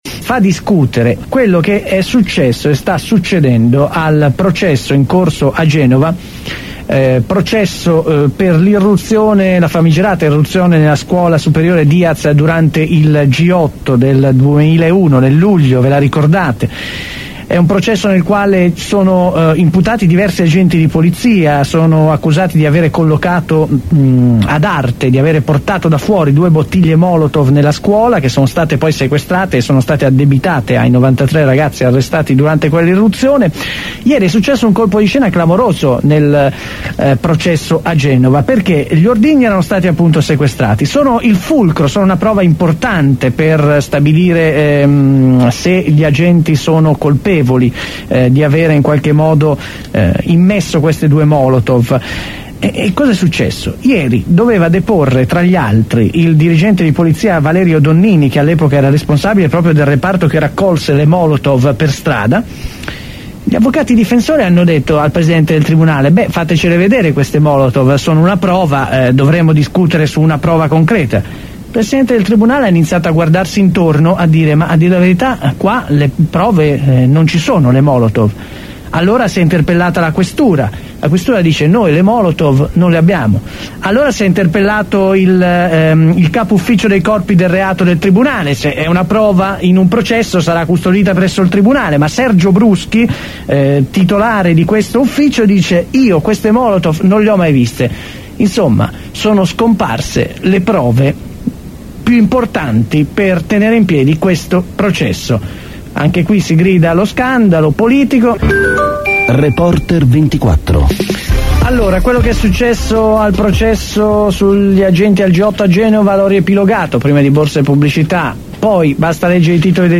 Con Vittorio Agnoletto Europarlamentare PRC e Senatore Alfredo Biondi (FI) Cronistoria dei fatti con articoli tratti da “La Repubblica”, “Il Manifesto”.